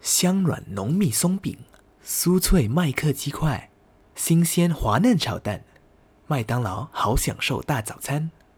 Male
Energetic Young Low Conversational
Mcdonald's Chinese Comfortable, relaxing